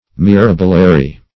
Search Result for " mirabilary" : The Collaborative International Dictionary of English v.0.48: Mirabilary \Mi*rab"i*la*ry\, n.; pl. Mirabilaries . One who, or a work which, narrates wonderful things; one who writes of wonders.
mirabilary.mp3